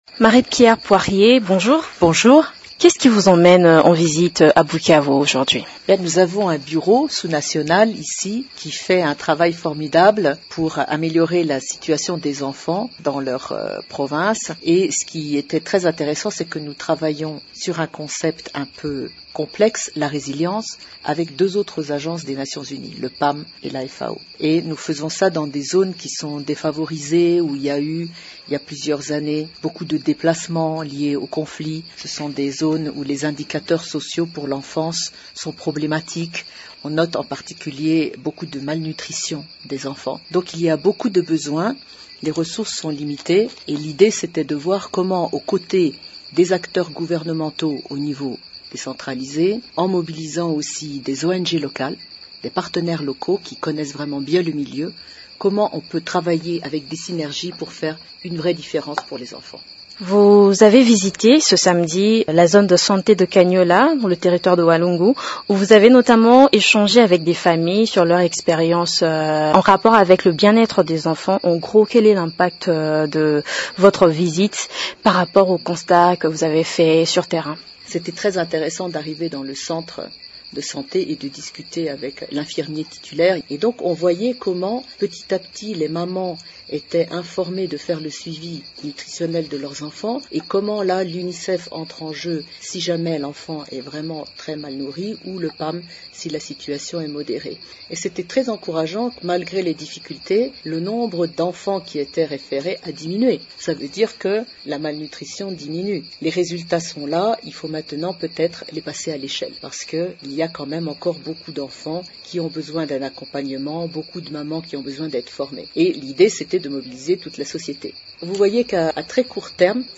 A l’issue de cette tournée, elle a declaré, dans un entretien à Radio Okapi, combien elle a pu réaliser l’apport du projet de résilience de l’UNICEF, PAM et FAO au sein des communautés.